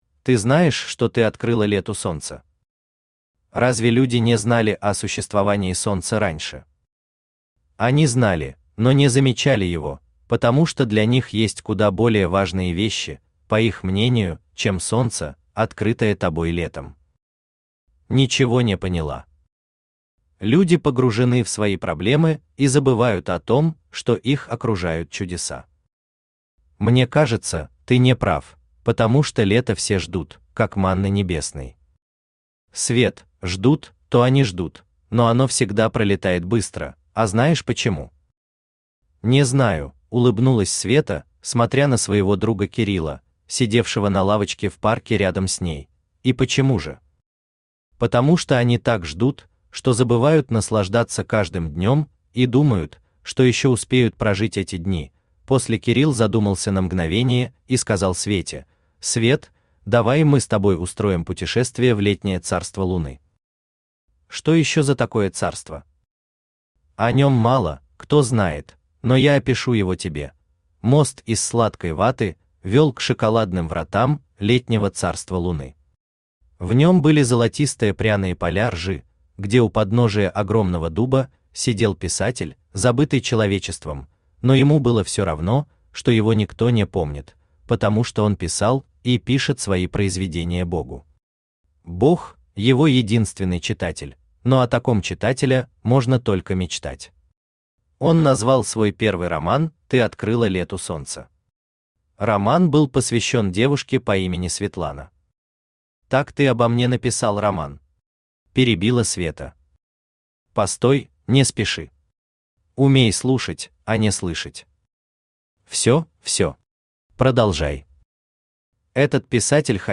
Aудиокнига Ты открыла Лету Солнце Автор Виталий Александрович Кириллов Читает аудиокнигу Авточтец ЛитРес.